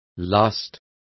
Complete with pronunciation of the translation of lusts.